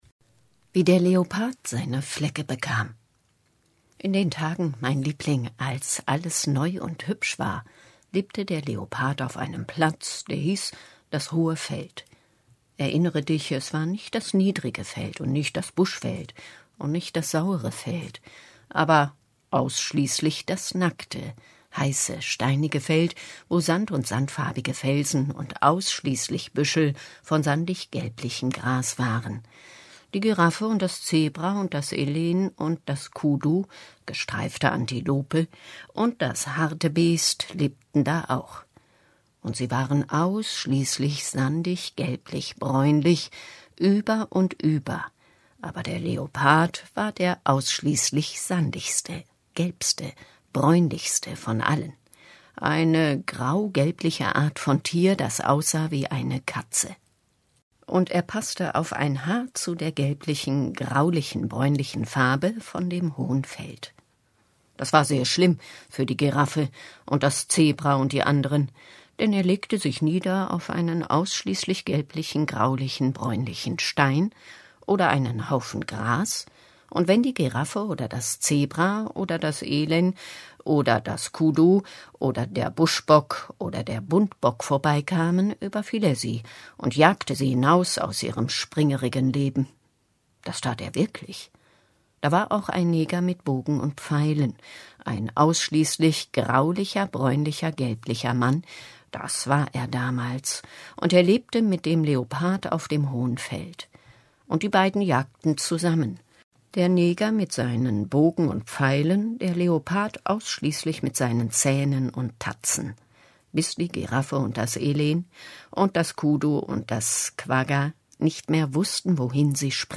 Das Buch wurde gelesen von Sprecherinnen der WBH, die Spieldauer beträgt etwa 3 Std.